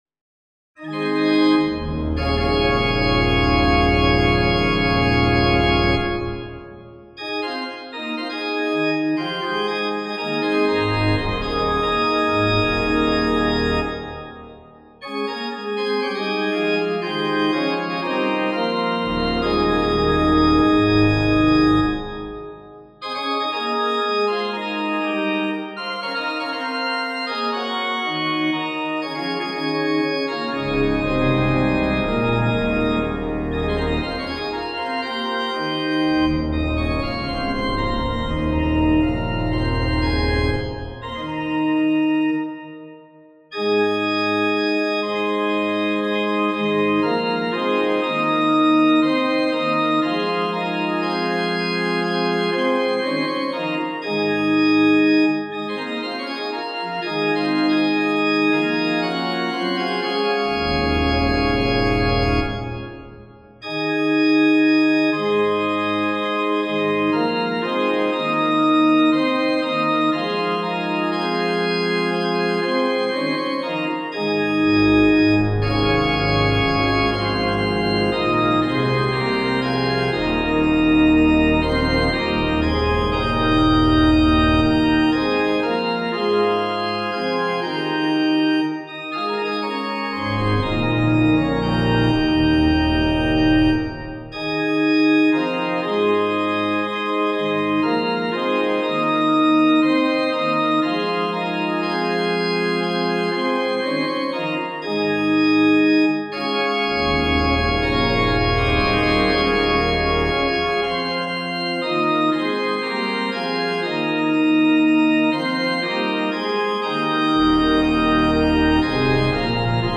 for organ
The open fifths, some parallelism and other "primitive" elements make the tune most interesting.